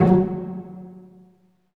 STR PIZZ.0DR.wav